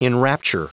Prononciation du mot enrapture en anglais (fichier audio)
Prononciation du mot : enrapture